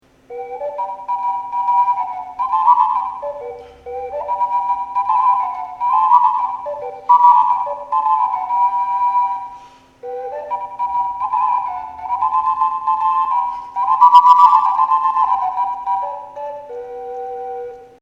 Ocarine Pacchioni (normali e speciali)
cornetta.mp3